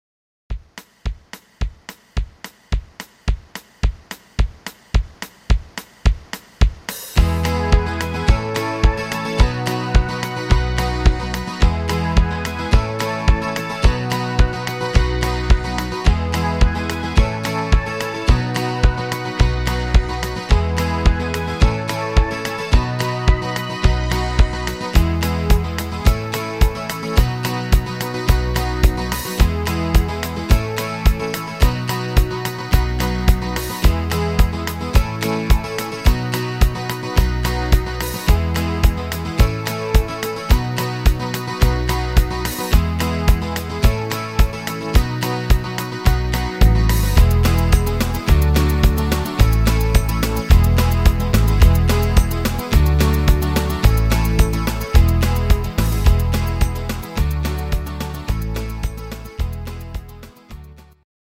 Rhythmus  8 Beat Shuffle
Art  Deutsch, Oldies, Österreich